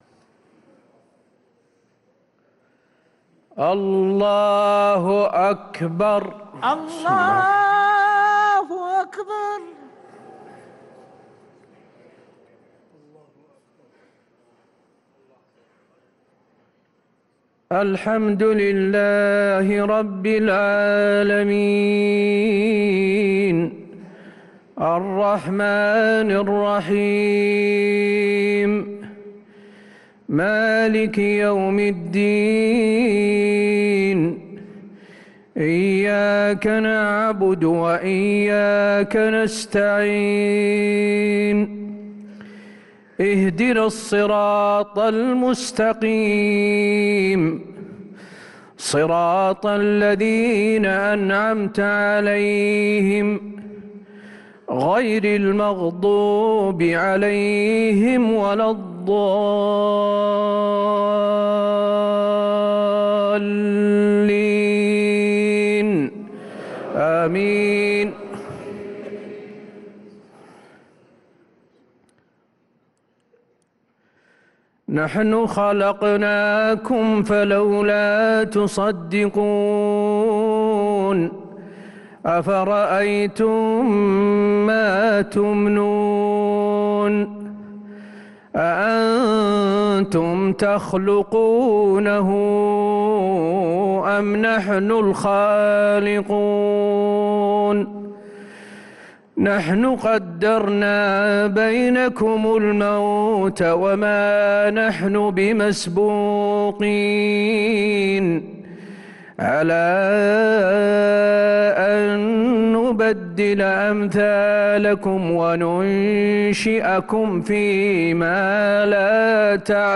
صلاة العشاء للقارئ حسين آل الشيخ 27 جمادي الآخر 1445 هـ
تِلَاوَات الْحَرَمَيْن .